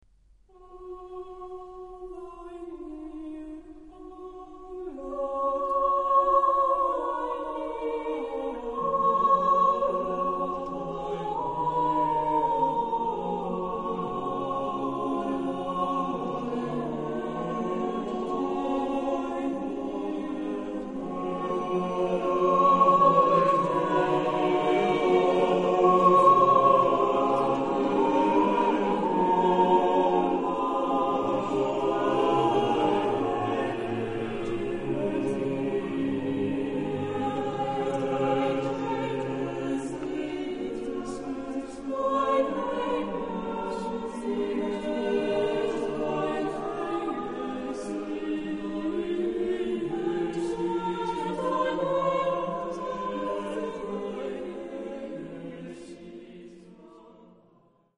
Genre-Style-Form: Sacred ; Anthem
Type of Choir: SATTB  (5 mixed voices )
Instruments: Organ (1)
Tonality: G major